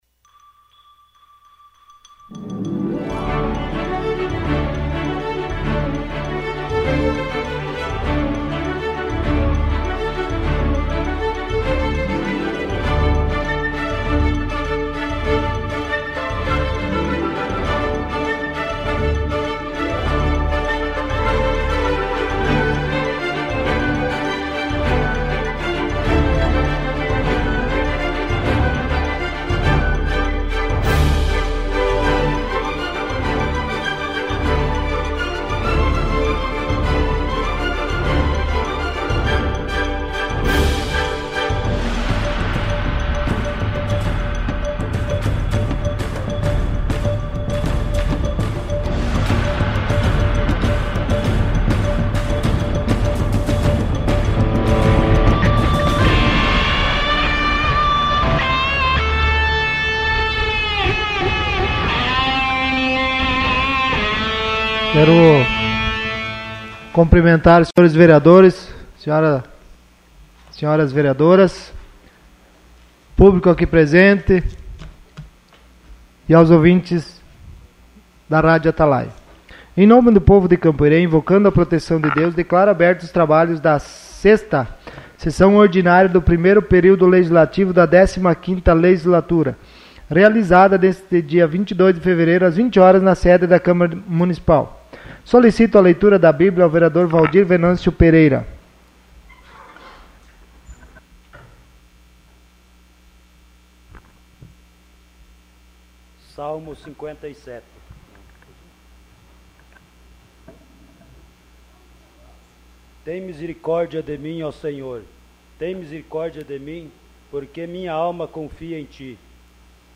Sessão Ordinária dia 22 de fevereiro de 2017.